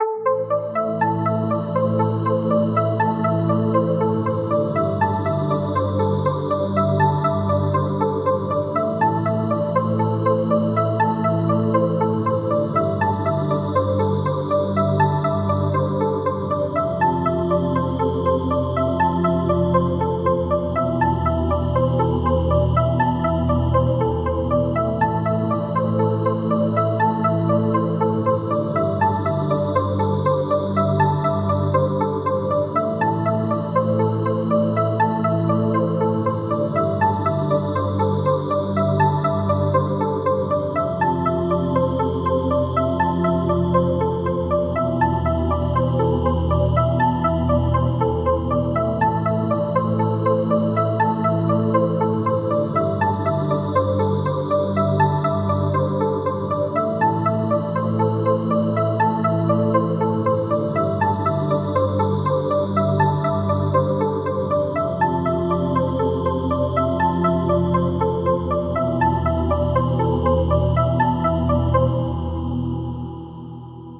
Added a few more ambient tracks.